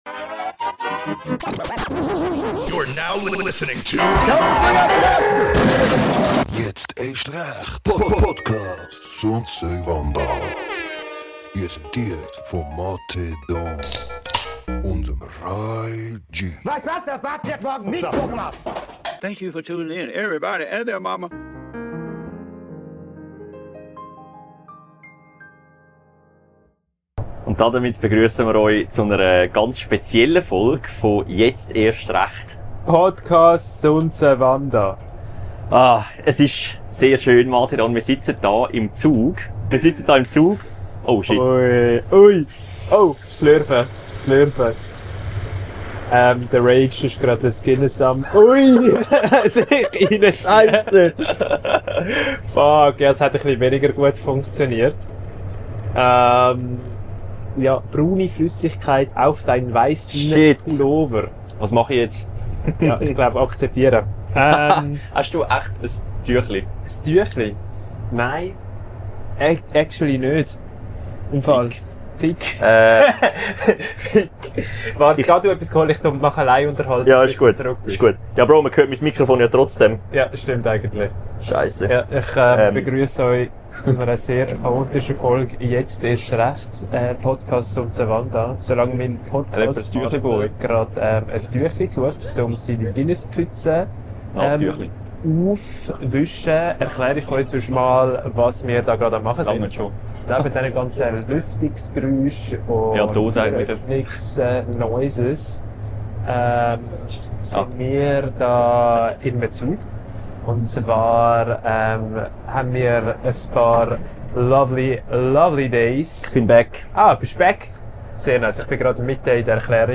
Ih dere Folg sitzemer im Zug vo Budapest zrugg uf Züri.